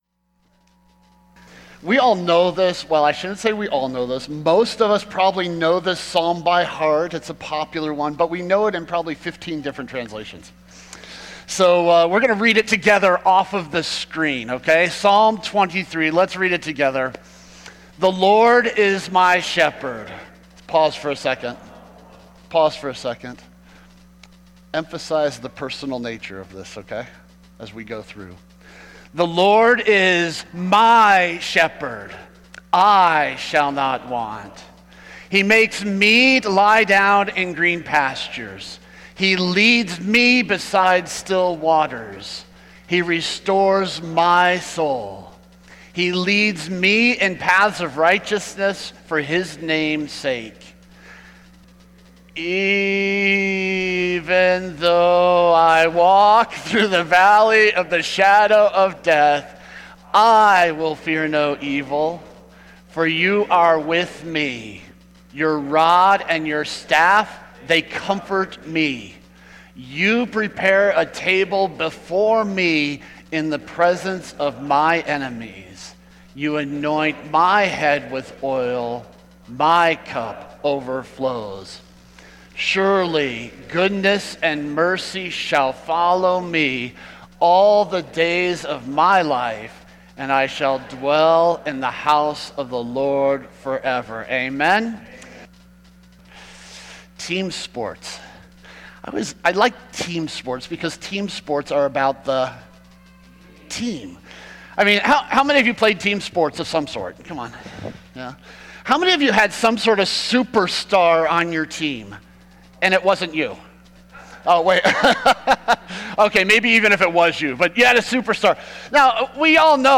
Sermons | The Rock of the C&MA